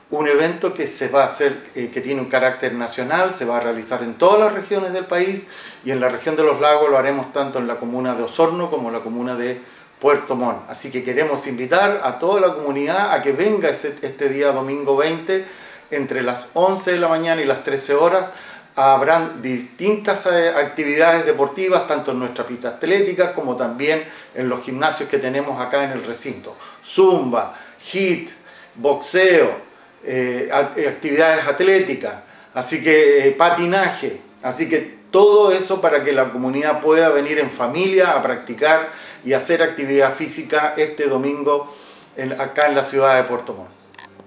El Director Regional del IND, Ernesto Villarroel Jaramillo, invitó a la comunidad a participar en esta fiesta deportiva – recreativa, mencionando que se realizará a nivel nacional, y en esta región, se hará tanto en Osorno como en Puerto Montt.